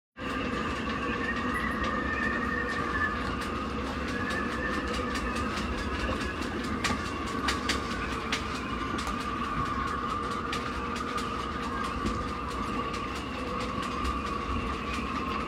WindWindWind.m4a